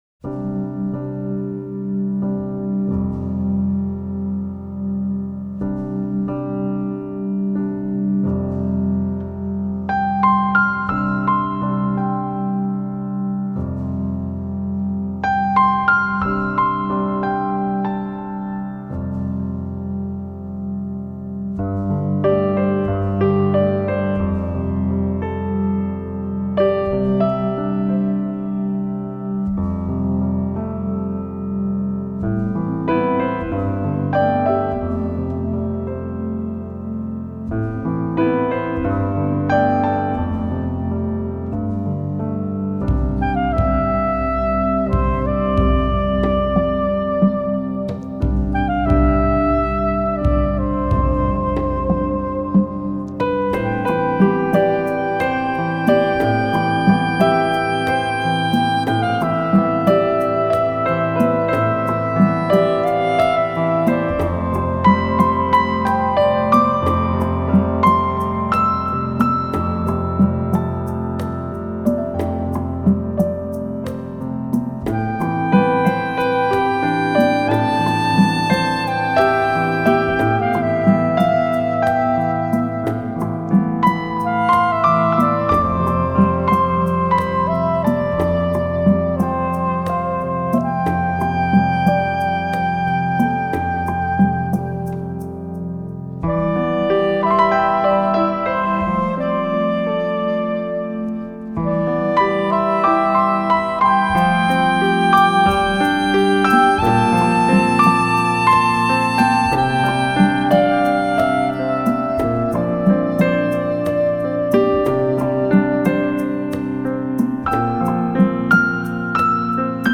NewAge